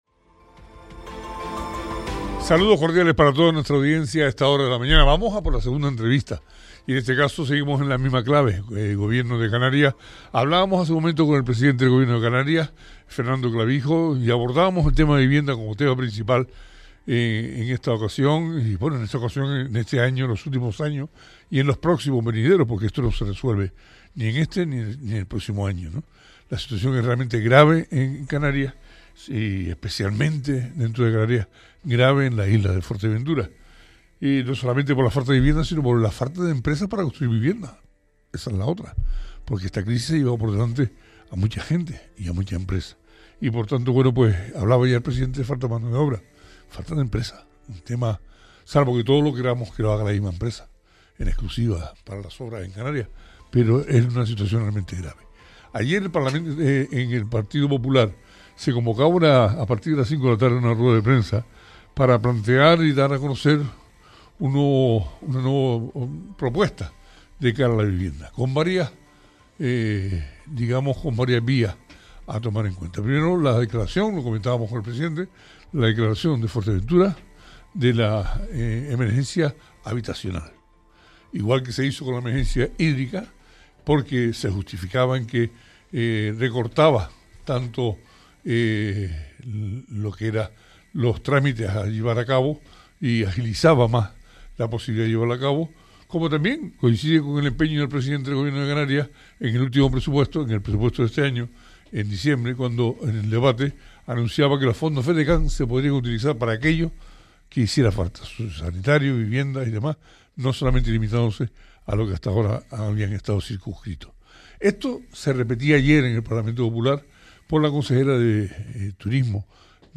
Entrevista a Jessica de León, consejera de Turismo y Empleo del Gobierno de Canarias - 27.02.26 - Radio Sintonía